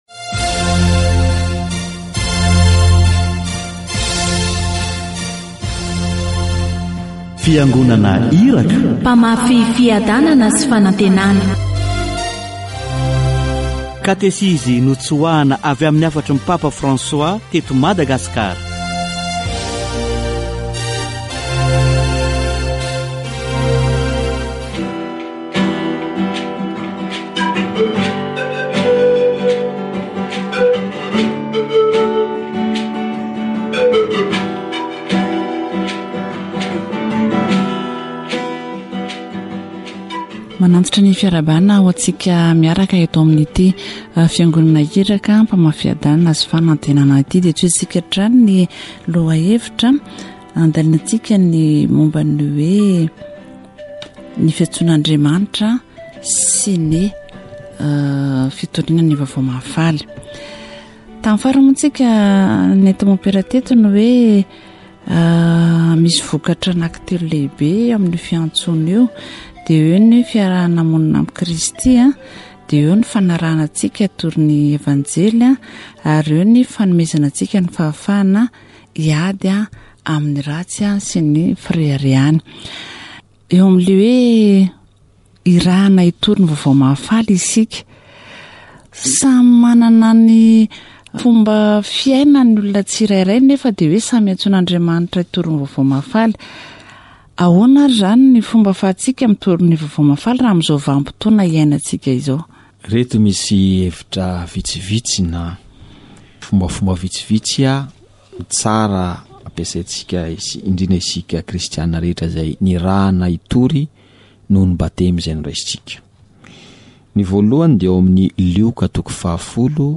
Catéchese sur "Prêcher l'Evangile"